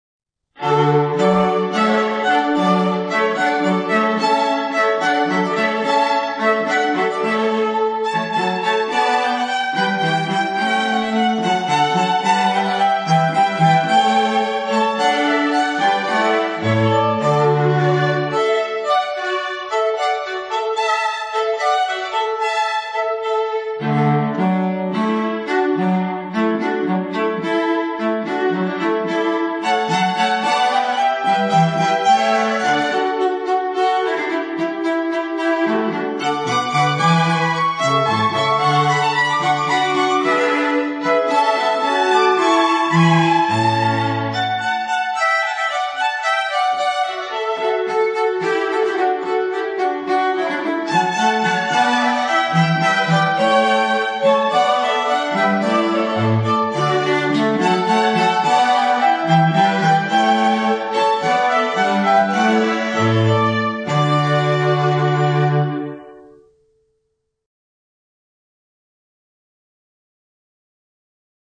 • Versatile, eclectic string quartet
A second violin, a viola and a 'cello complete the standard line-up.